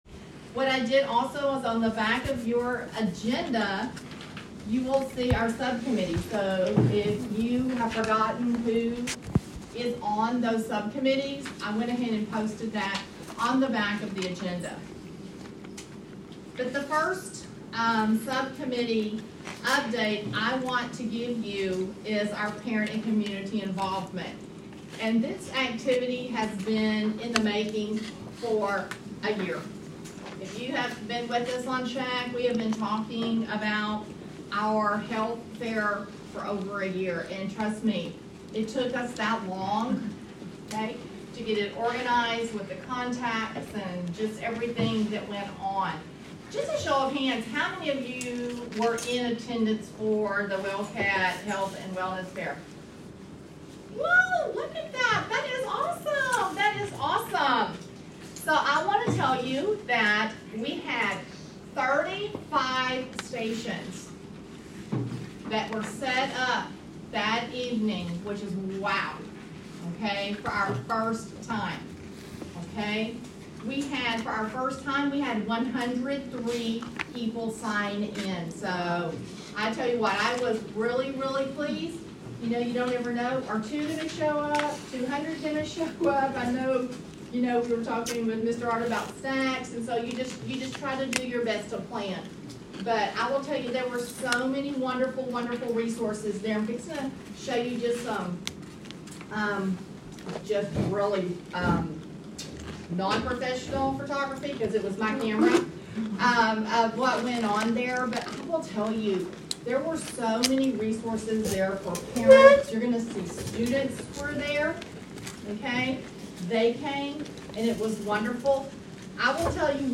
All meetings will be conducted at the Onalaska Independent School District Professional Development Center.